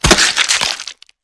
2D-Platformer/Assets/Sounds/Impacts/Bug/crack12.mp3.wav at master
crack12.mp3.wav